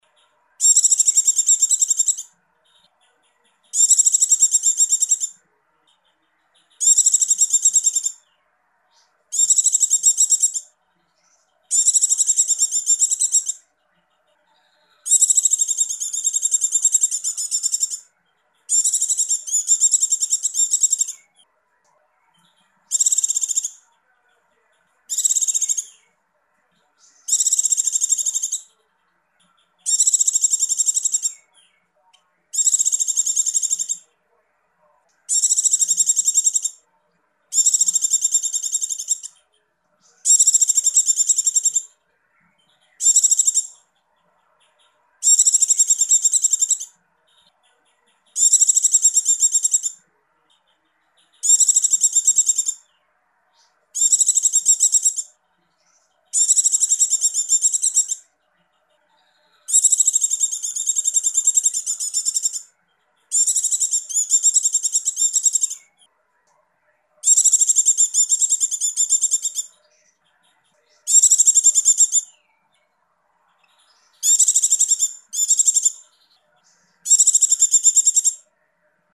Download Suara Burung Pleci Mp3 Durasi Panjang
Suara Pleci Ngecir